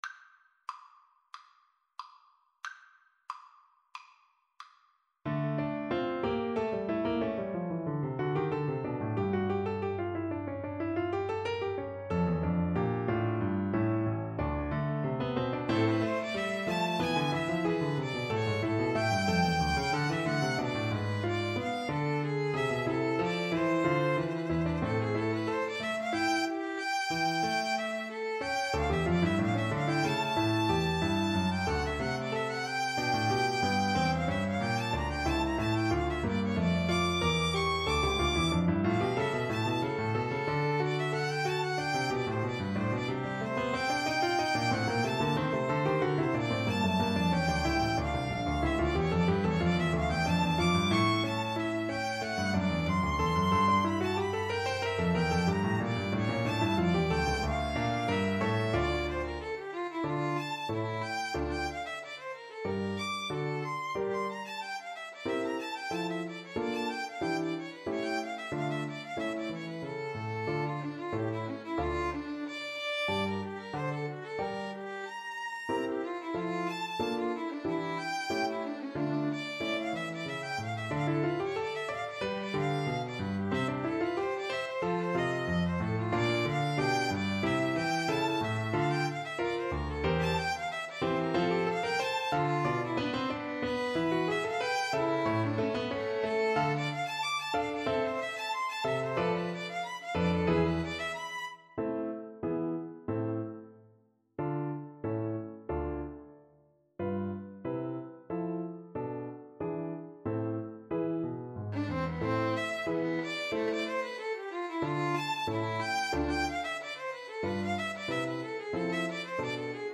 = 92 I: Vivace (View more music marked Vivace)
4/4 (View more 4/4 Music)
Violin Duet  (View more Advanced Violin Duet Music)
Classical (View more Classical Violin Duet Music)